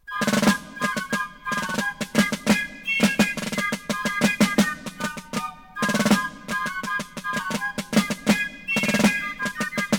Dudelsagg.ogg